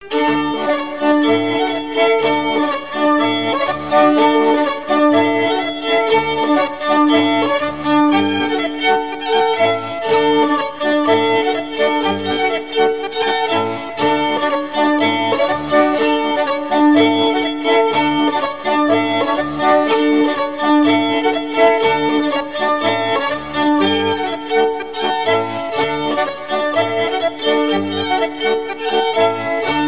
danse : ronde
circonstance : rencontre de sonneurs de trompe
Pièce musicale éditée